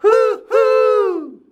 HUHUUUH C.wav